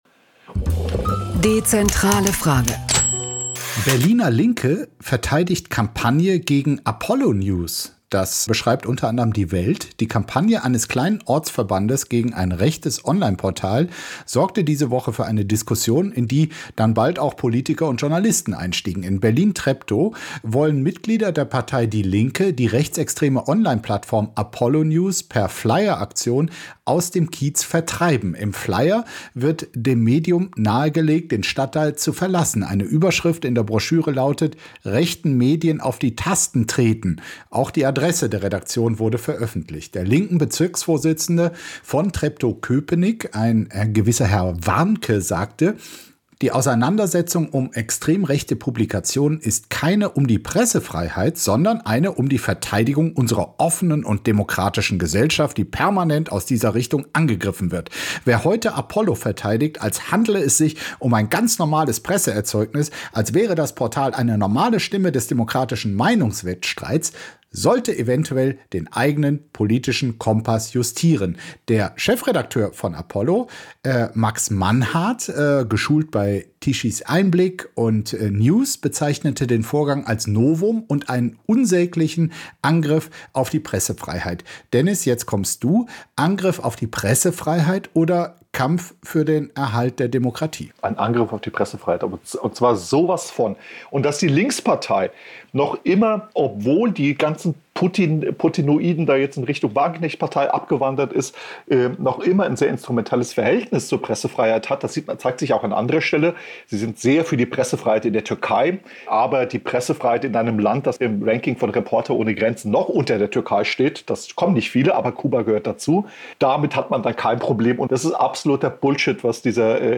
Apokalypse & Filterkaffee [Podcast], Gespräch von Markus Feldenkirchen mit Deniz Yücel, 9.